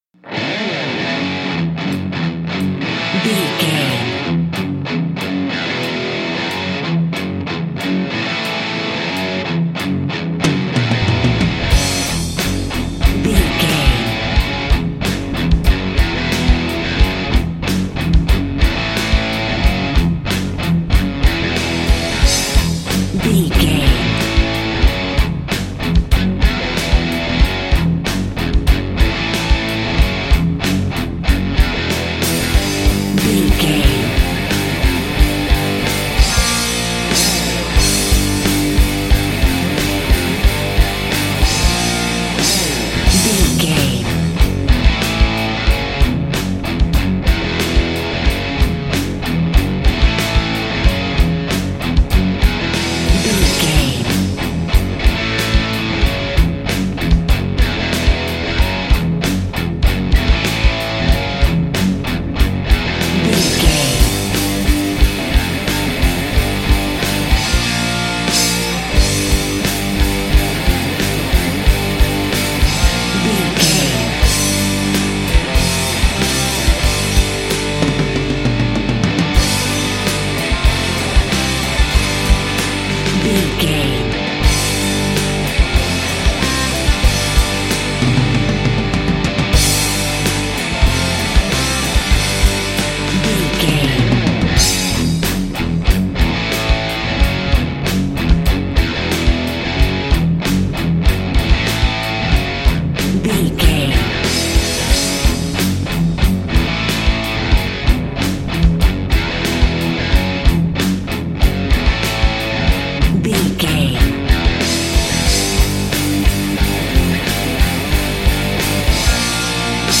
Aeolian/Minor
F#
drums
electric guitar
bass guitar
hard rock
aggressive
energetic
intense
nu metal
alternative metal